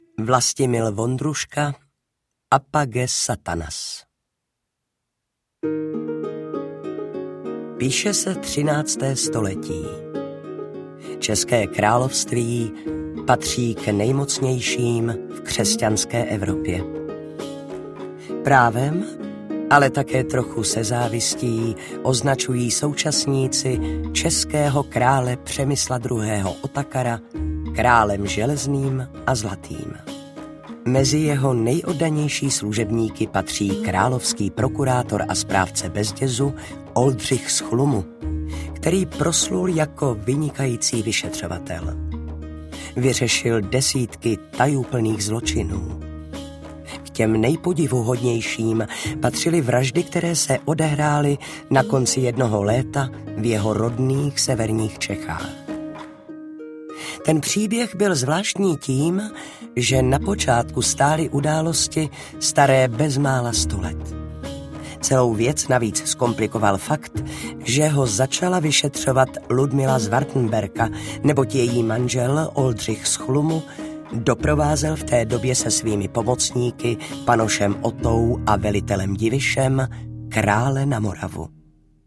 Apage Satanas audiokniha
Ukázka z knihy
• InterpretVladislav Beneš, Valérie Zawadská